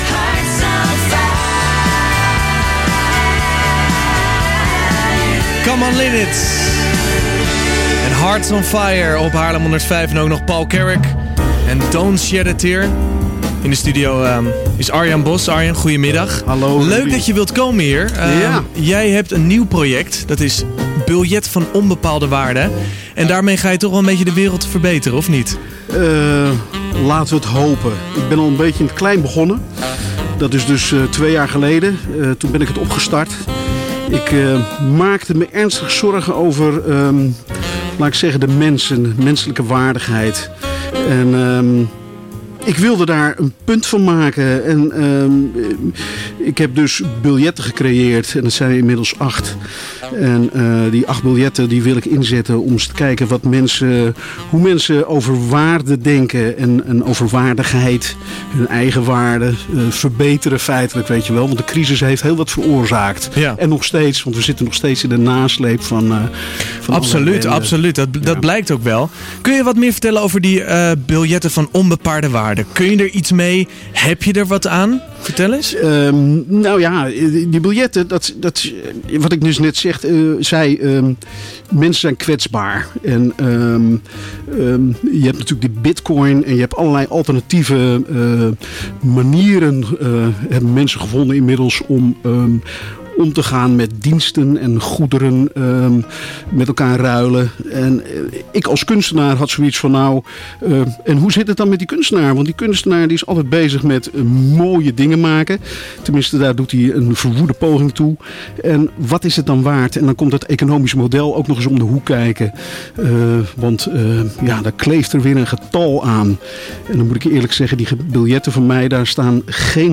Interview bij Radio 105 Haarlem over Van Onbepaalde Waarde en de Kunstlijn 2015